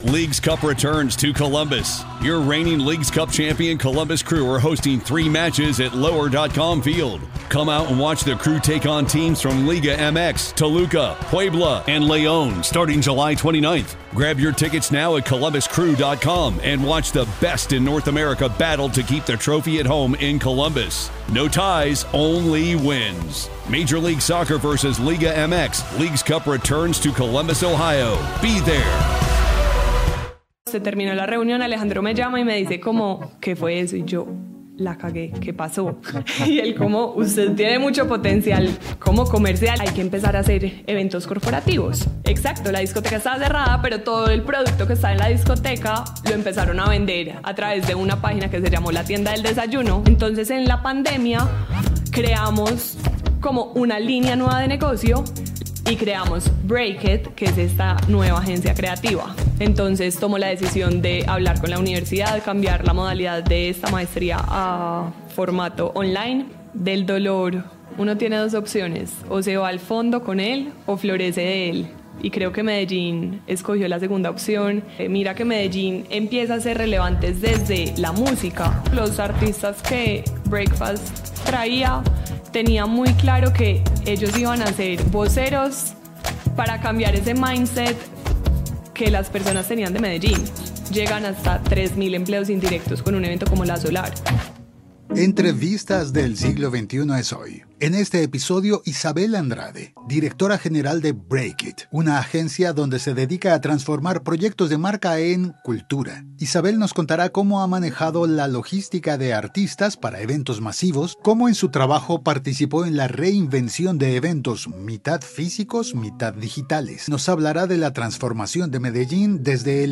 Entrevistas del Archivo